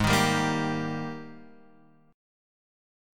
G# Major 7th Suspended 2nd